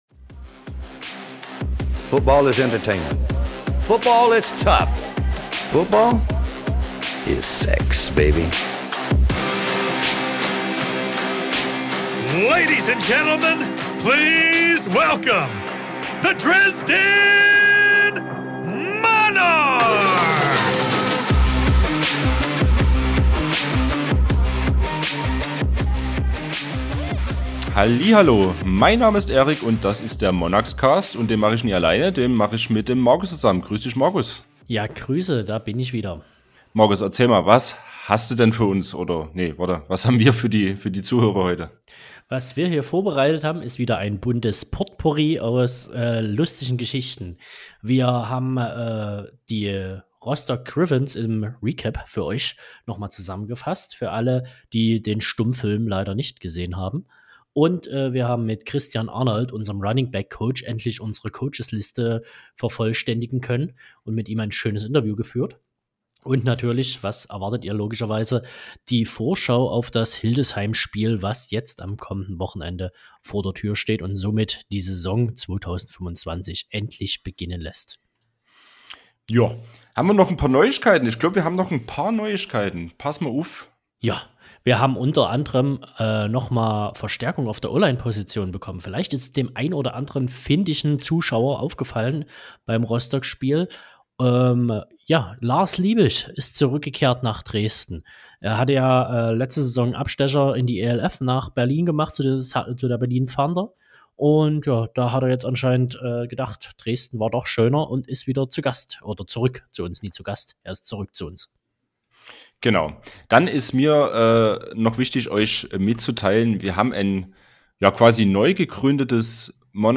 Außerdem besprechen wir, natürlich, unsere Eindrücke zum zweiten Testspiel gegen die Rostock Griffins. Ein Gast darf natürlich auch diesmal nicht fehlen.